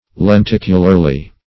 lenticularly - definition of lenticularly - synonyms, pronunciation, spelling from Free Dictionary Search Result for " lenticularly" : The Collaborative International Dictionary of English v.0.48: Lenticularly \Len*tic"u*lar*ly\, adv. In the manner of a lens; with a curve.